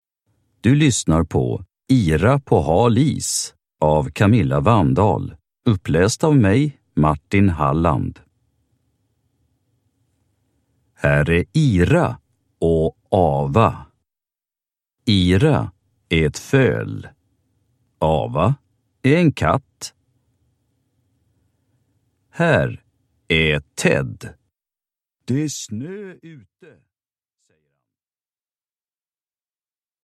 Ira på hal is – Ljudbok